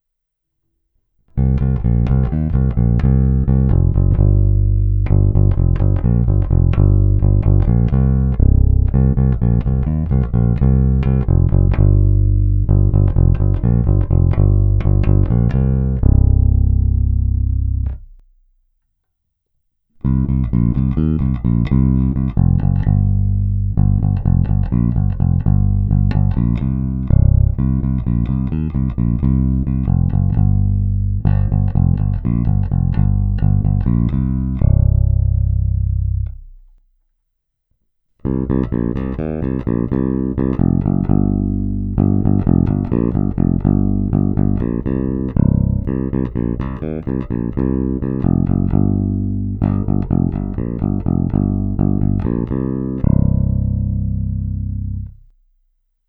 Skvělý zvuk na prsty, stejně tak na slap.
Není-li uvedeno jinak, následující nahrávky jsou provedeny rovnou do zvukové karty a s plně otevřenou tónovou clonou.